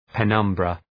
Shkrimi fonetik {pı’nʌmbrə}